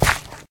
sounds / gravel / step1.wav
Added dirt and gravel sound
step1.wav